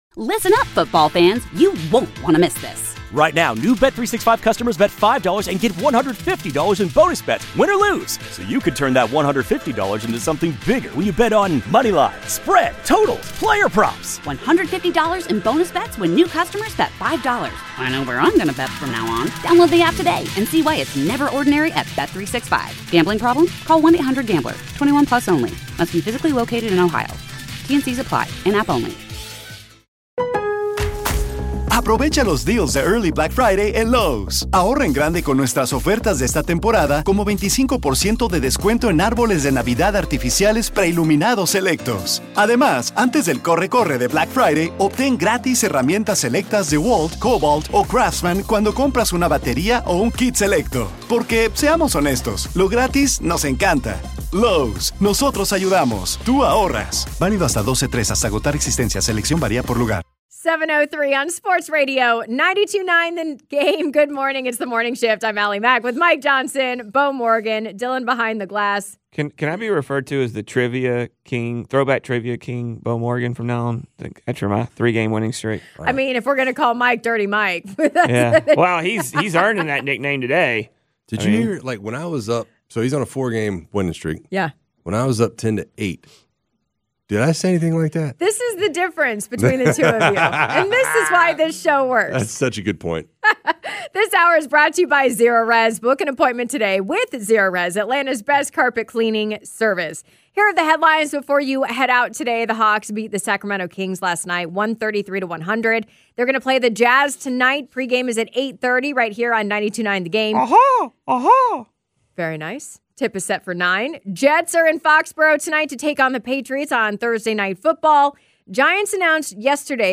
let you hear part of Atlanta Falcons Head Coach Raheem Morris’ interview